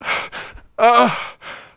1 channel
welder-inpain2.wav